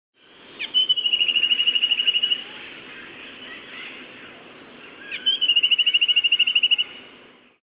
Ramphocaenus melanurus (long-billed gnatwren)
This is an example of a Long-billed Gnatwren (Ramphocaenus melanurus) recorded on the road leading out of Bribri, near Puerto Viejo de Talamanca (Costa Rica), 6/22/99.